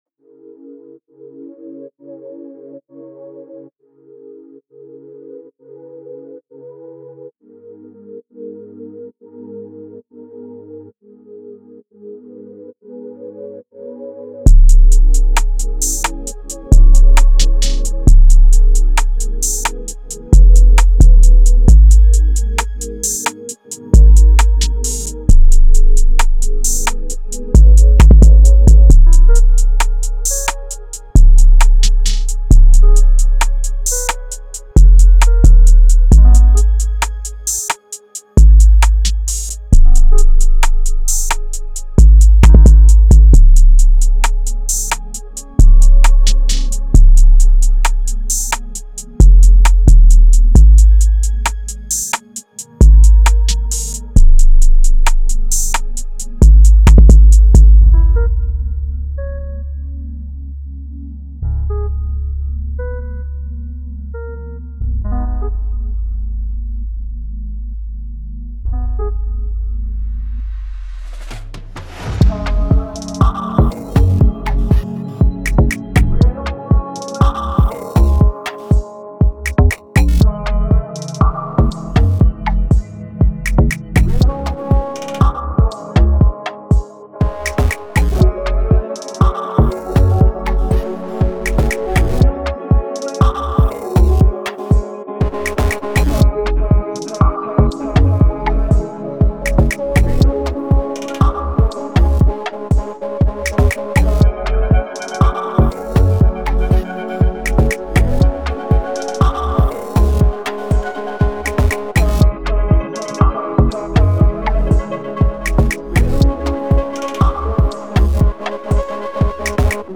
Trap & Jersey
120 G# - C# Minor